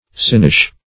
sinewish - definition of sinewish - synonyms, pronunciation, spelling from Free Dictionary Search Result for " sinewish" : The Collaborative International Dictionary of English v.0.48: Sinewish \Sin"ew*ish\, a. Sinewy.